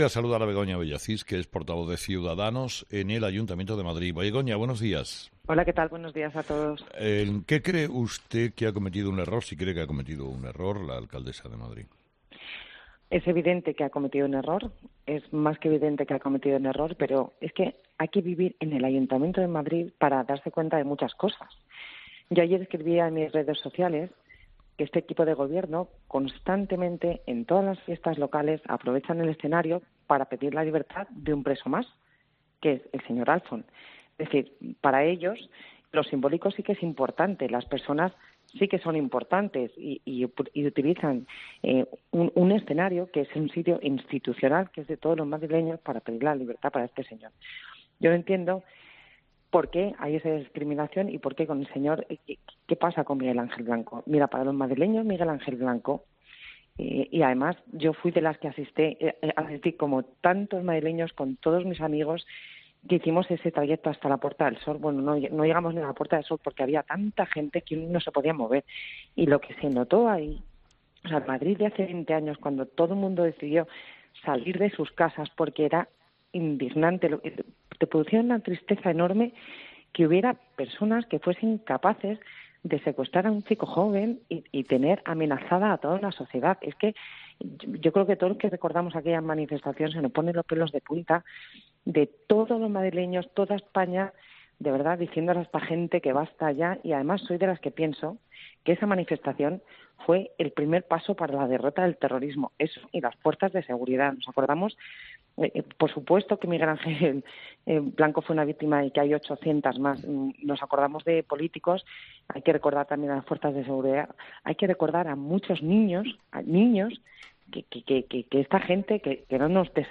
Begoña Villacís, portavoz municipal de Ciudadanos en el Ayuntamiento de Madrid